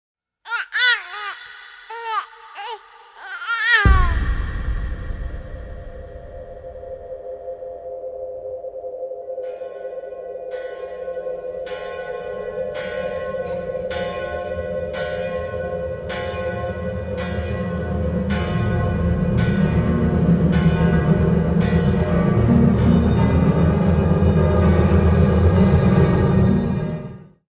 Voices frolm the following cultures:
Buriyart, Arabic, Inuit, Xingu
piano quote